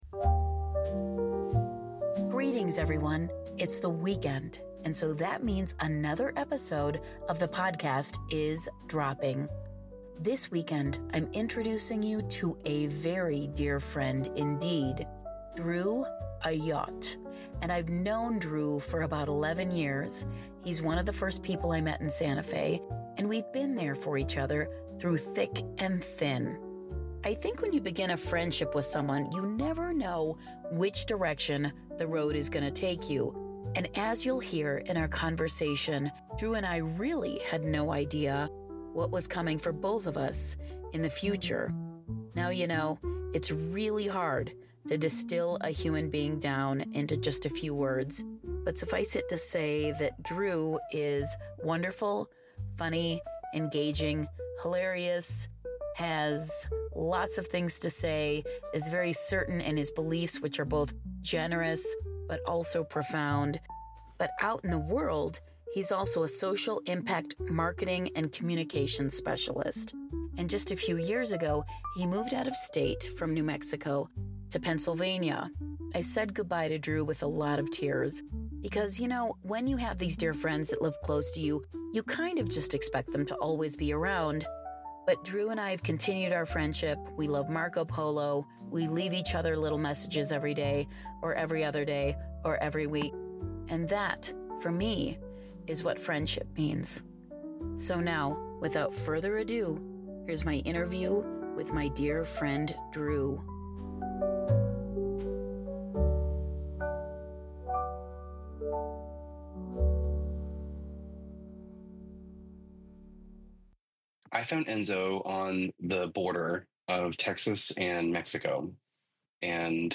Byte Sized Blessings / Interview